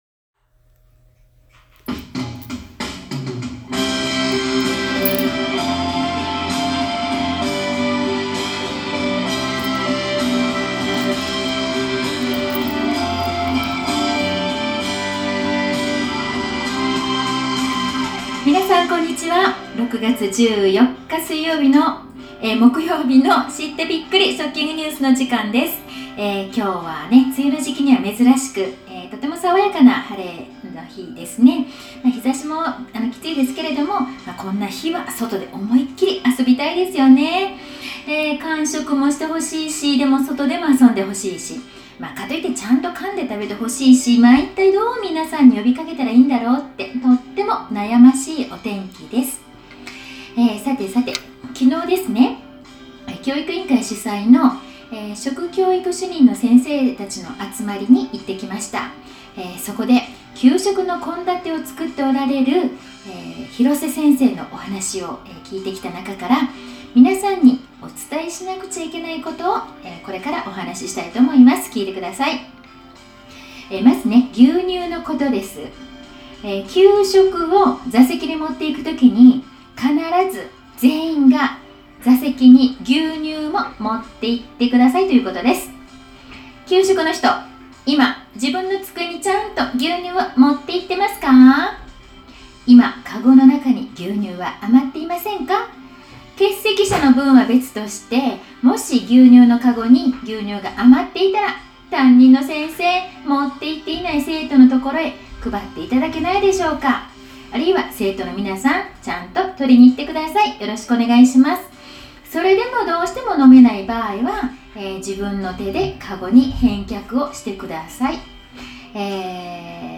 今日の給食＆食育放送